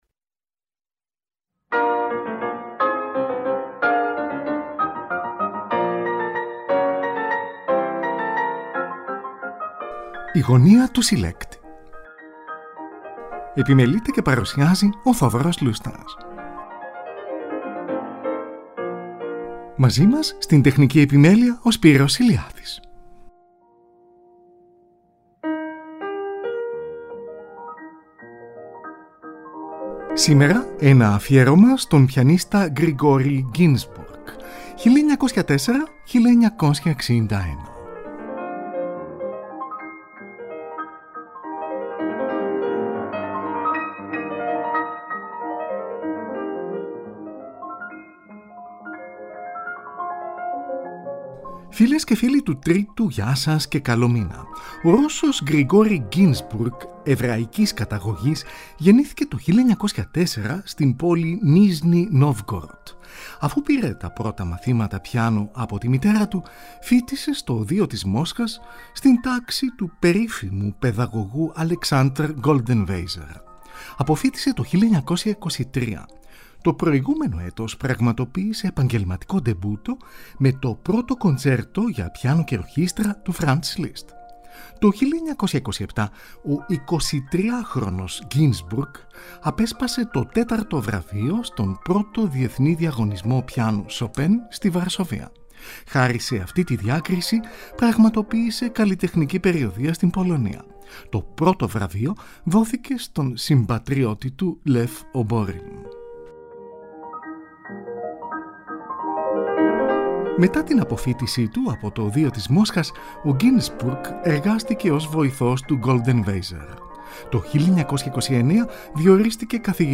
ΑΦΙΕΡΩΜΑ ΣΤΟΝ ΡΩΣΟ ΠΙΑΝΙΣΤΑ GRIGORY GINZBURG (1904-1961)